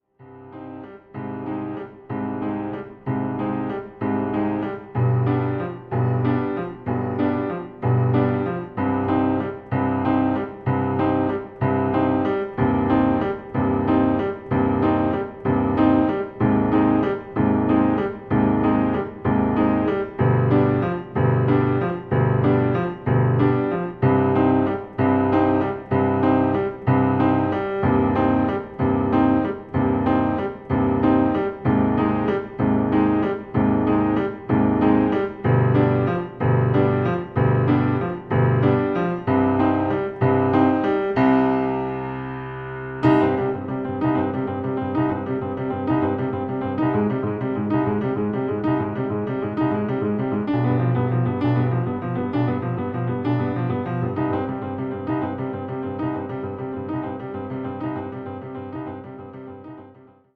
pianoforte
dall'atmosfera malinconica ma affascinante